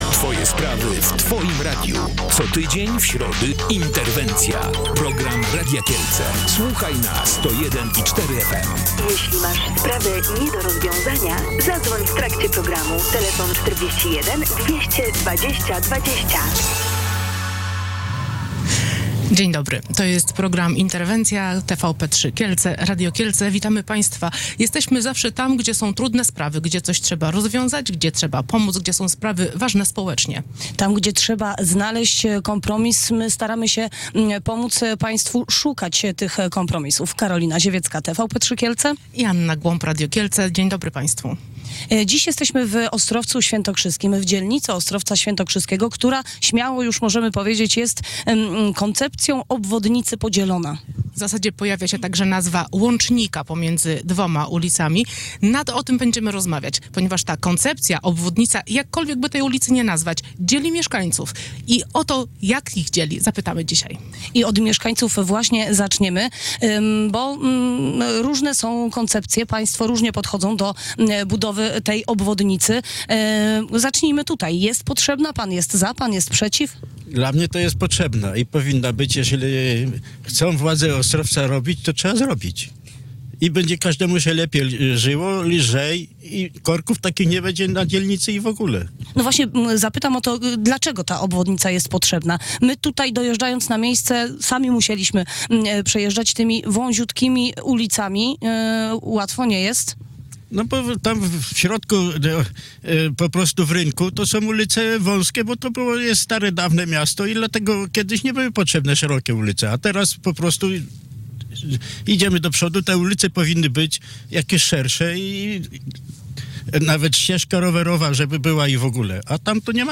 Łącznik ulic Chrzanowskiego i Samsonowicza, biegnący przez osiedle Denków w Ostrowcu Świętokrzyskim dzieli mieszkańców. Część z nich chce, by przebiegał wyznaczonym przez urząd miasta szlakiem, część natomiast postuluje wdrożenie innej koncepcji, czyli poprowadzenia mostu nad Kamienną. Na ten temat rozmawialiśmy w programie Radia Kielce i TVP3 Kielce „Interwencja” w środę (7 stycznia).